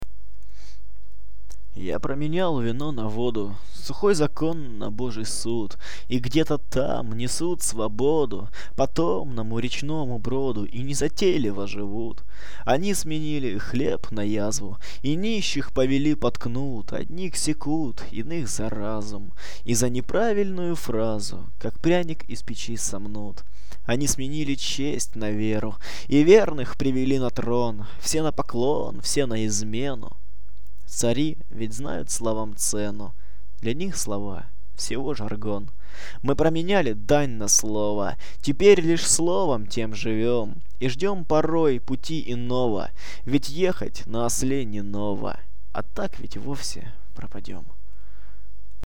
а ГОЛОС! РОК! ЛЮБОВЬ!
.... у меня голос =) я его сам боюсь.. противный он у меня.*(